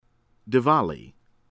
DISSANAYAKE, S.B. dee-sahn-EYE-ah-keh